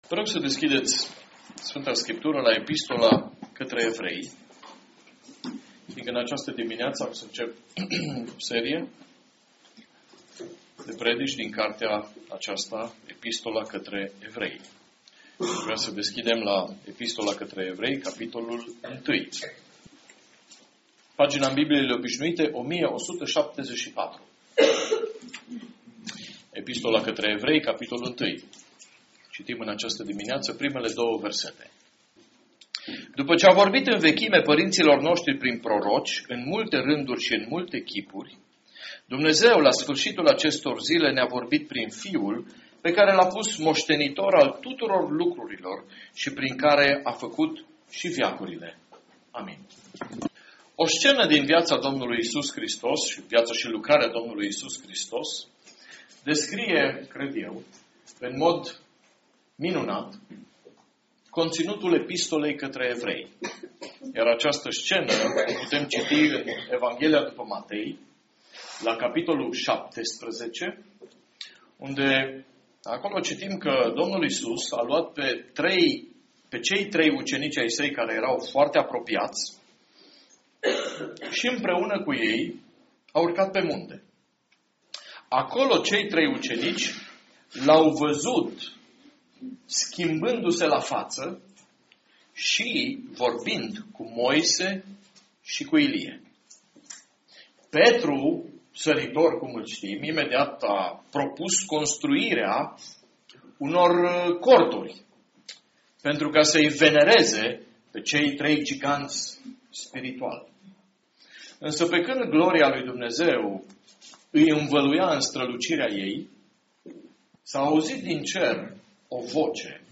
Podcast predici